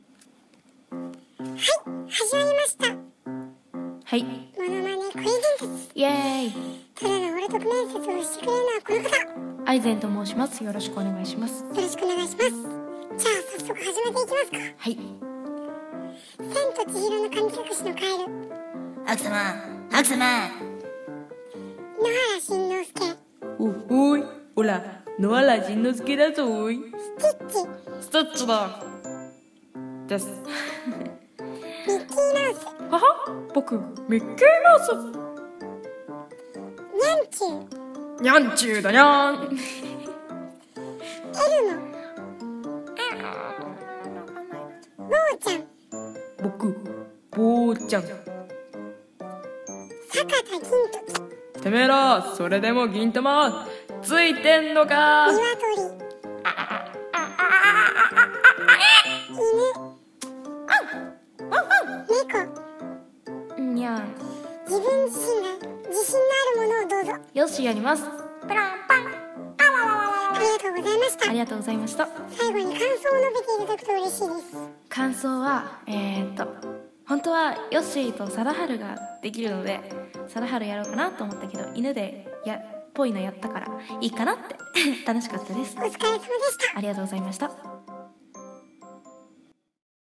ものまね声面接！！！！！